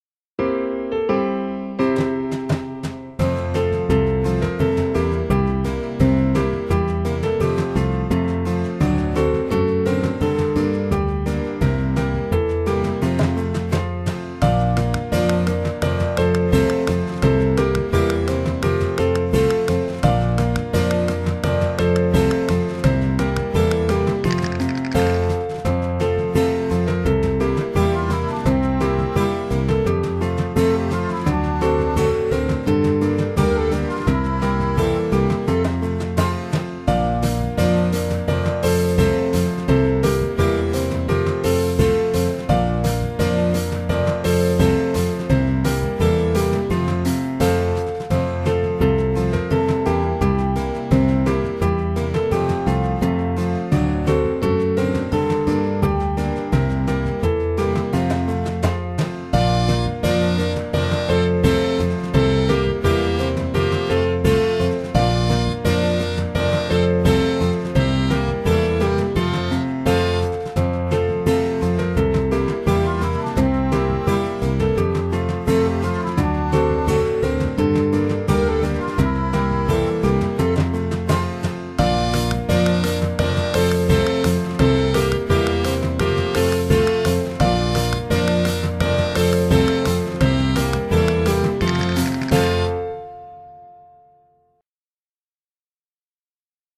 Музыканты Песня-игра с музыкальными инструментами. Текст: В игре с шумовыми инструментами дети поделены на группы. У одной группы ложки, у другой бубны.